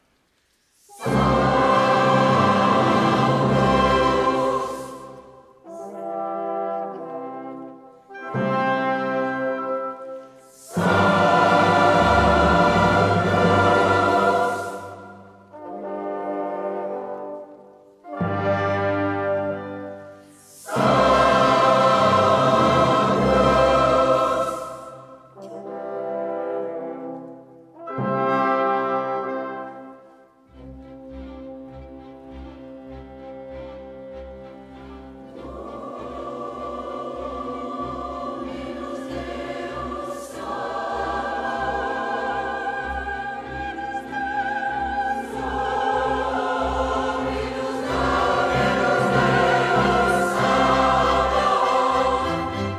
Le Chant classique amateur en Puy-de-Dôme
Chorale Crescendo ROMAGNAT
Groupe La Chamade Clermont-Fd
Le Choeur de Riom
et 35 musiciens professionnels
DOUBLE-CD LIVE
Les extraits de l'enregistrement des 16 et 17/06/2012 à Maison de la Culture de Clermont-Fd :